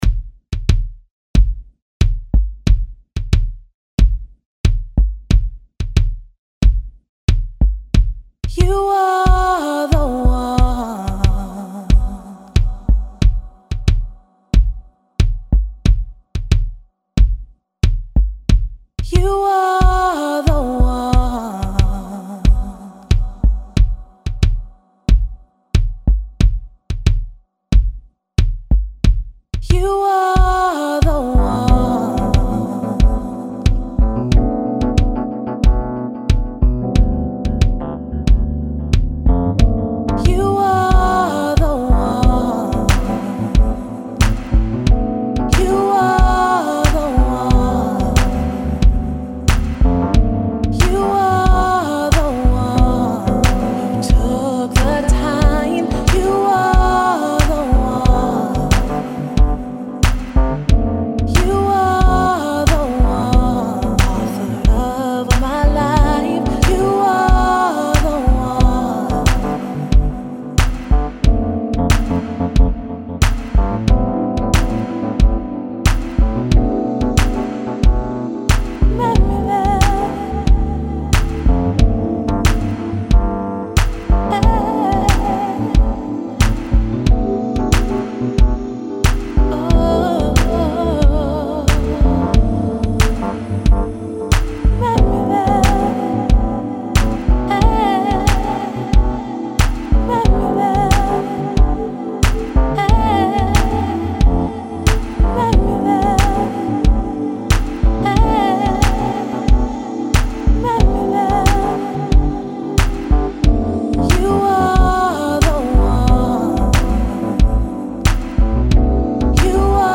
It’s on the smoother side of things…hope u enjoy